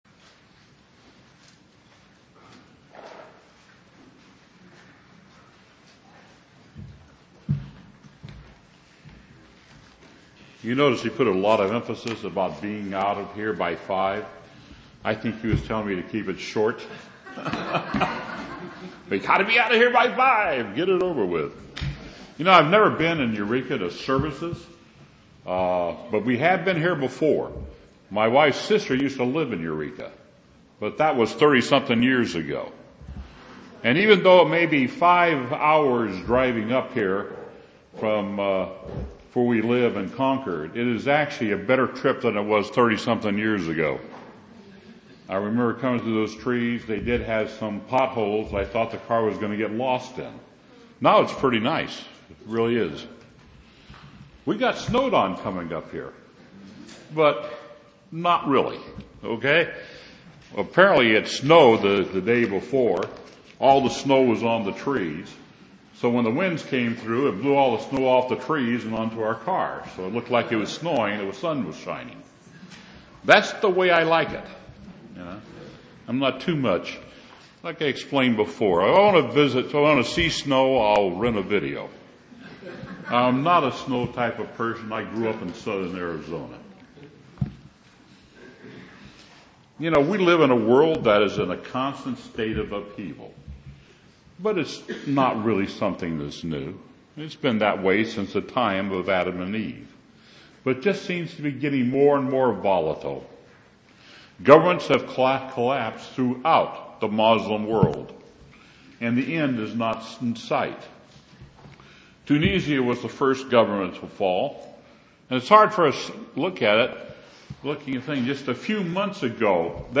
UCG Sermon Studying the bible?
Surviving in Satan's World Given on Feb 26, 2011 by Given in Eureka, CA Downloads Downloads Download Audio To download a file, right-click (or long press) a link above and choose Save As .